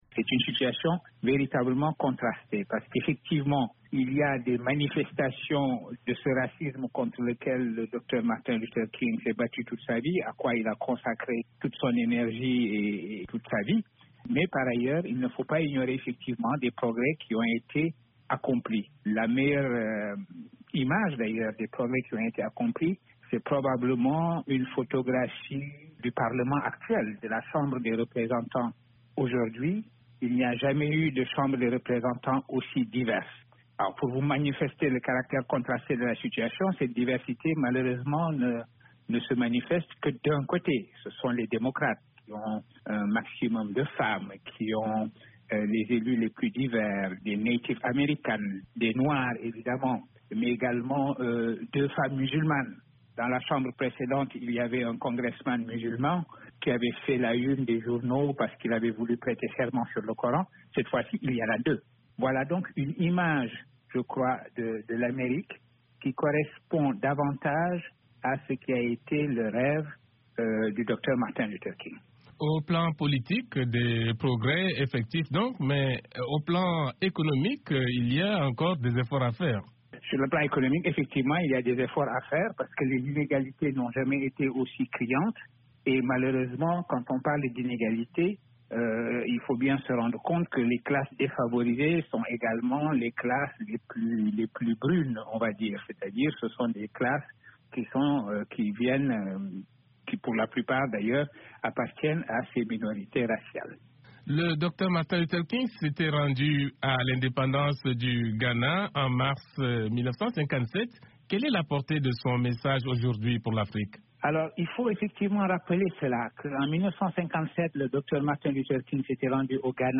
s’est entretenu à ce sujet avec le professeur Souleymane Bachir Diagne de l’Université Columbia à New York.